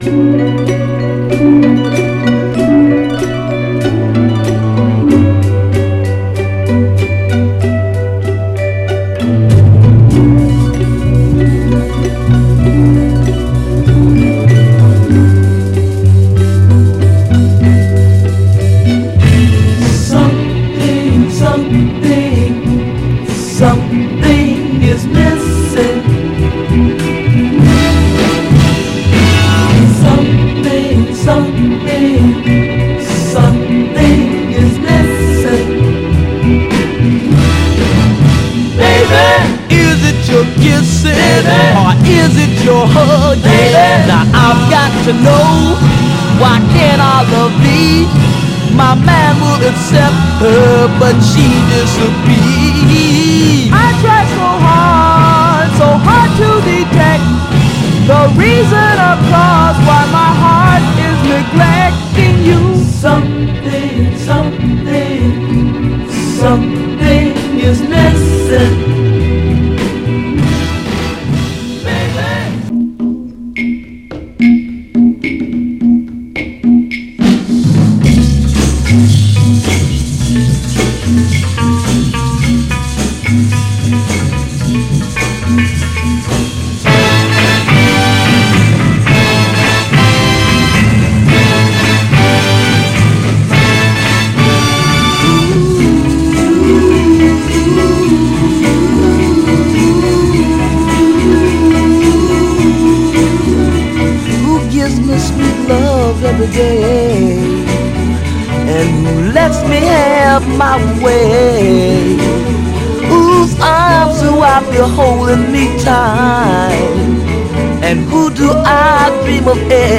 美しいシカゴ・ソウル・ステッパー
盤はごく細かい表面スレありますが、グロスがありプレイ良好です。
※試聴音源は実際にお送りする商品から録音したものです※